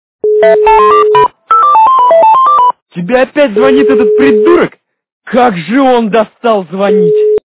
» Звуки » Смешные » Мужской голос - Опять звонит этот придурок. Как он достал звонить.
При прослушивании Мужской голос - Опять звонит этот придурок. Как он достал звонить. качество понижено и присутствуют гудки.